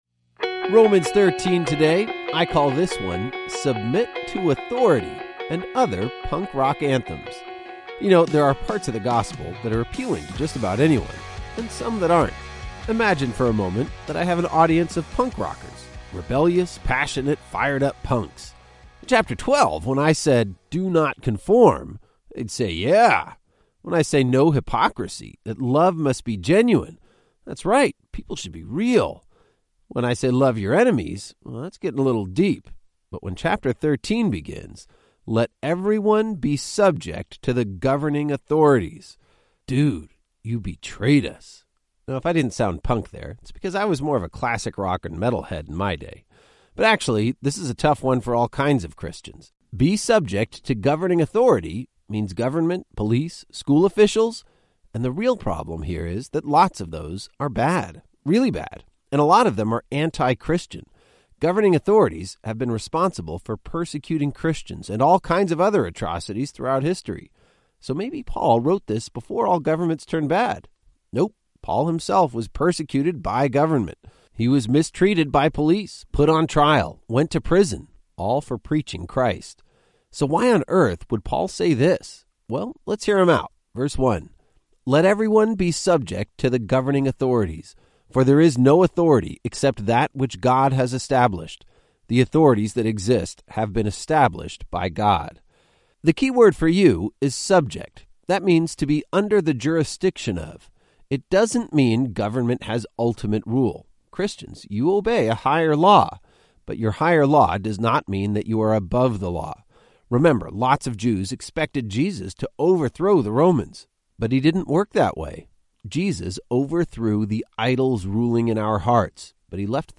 19 Journeys is a daily audio guide to the entire Bible, one chapter at a time.